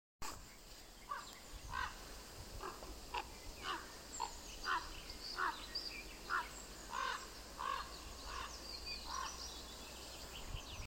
ворон, Corvus corax
Ziņotāja saglabāts vietas nosaukumsDaļēji izcirsts mežs
СтатусПара в подходящем для гнездования биотопе